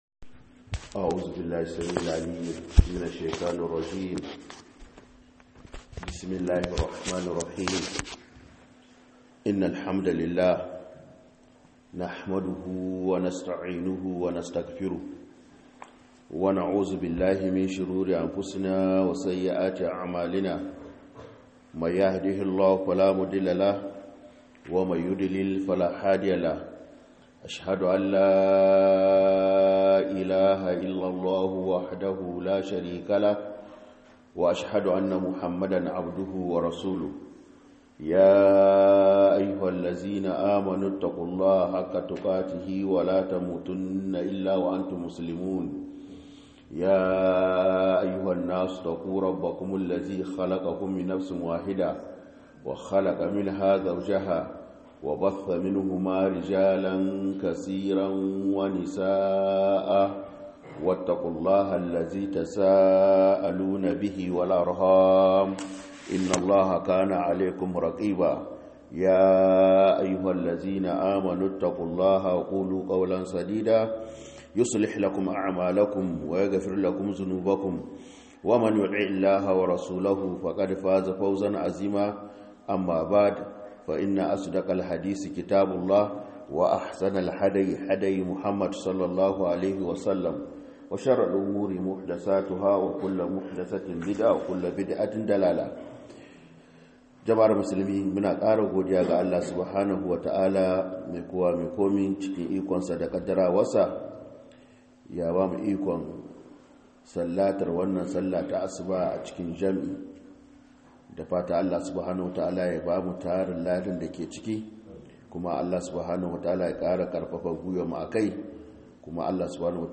Falalar Ramadan da kula da masallatai - MUHADARA